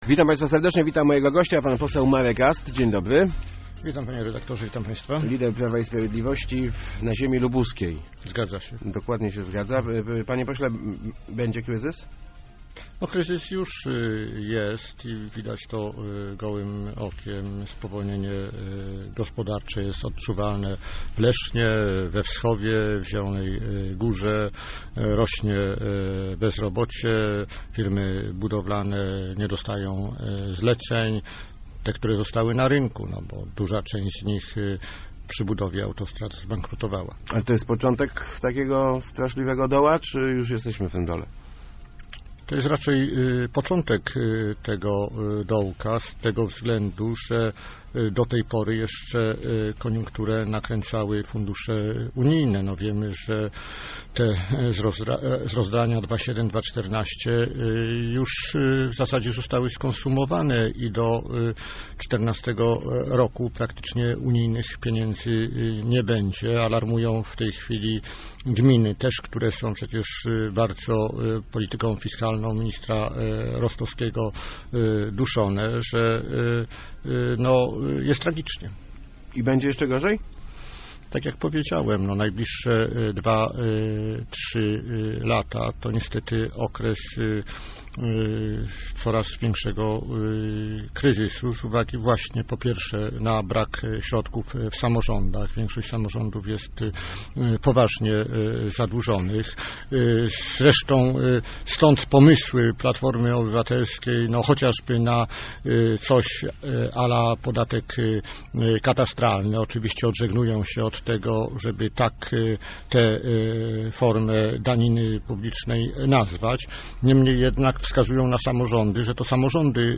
mast80.jpgJeżeli przejmeimy władzę, NFZ zostanie zlikwidowany w ciągu pół roku - mówił w Rozmowach Elki poseł PiS Marek Ast. Jego partia przedstawiła szereg propozycji reform na trudne czasy; wśród nich są między innymi podatki dla banków i hipermarketów. Zapytany dlaczego nie prowadzono ich podczas rządów Jarosława Kaczyńskiego Ast odpowiada krótko: mieliśmy zbyt mało czasu.